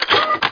shotgnck.mp3